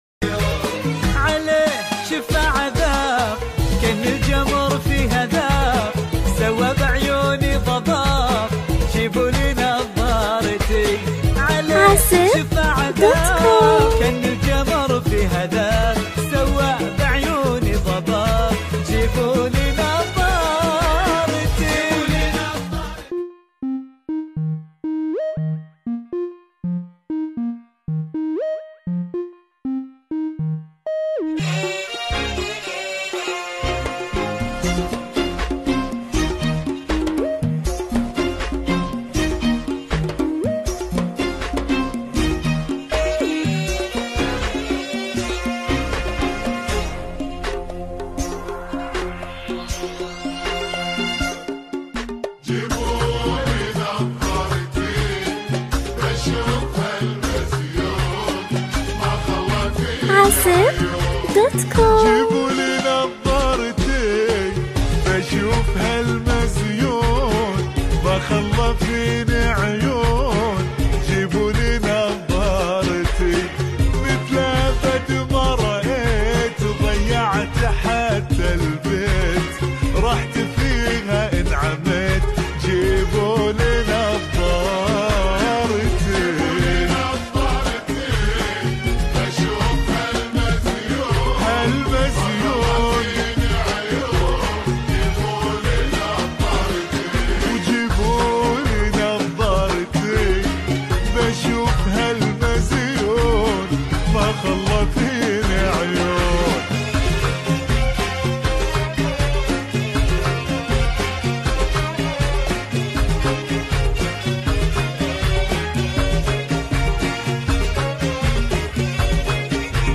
تبطيء